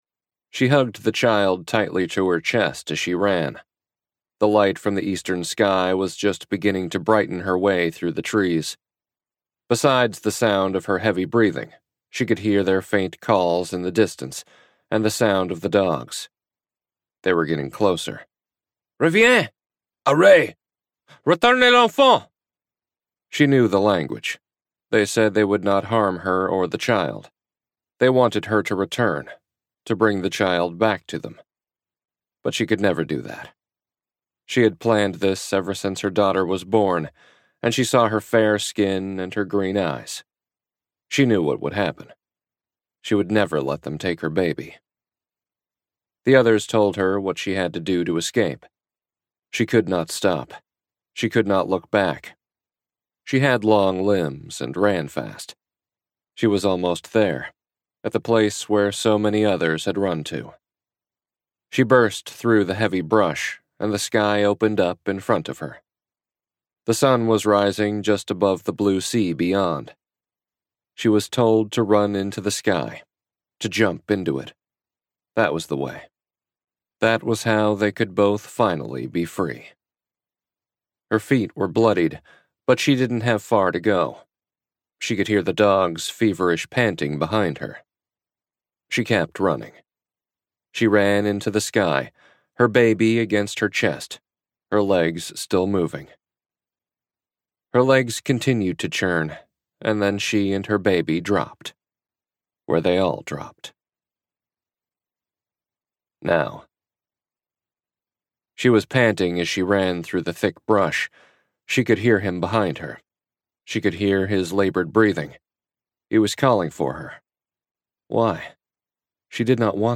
Freedom Drop - Vibrance Press Audiobooks - Vibrance Press Audiobooks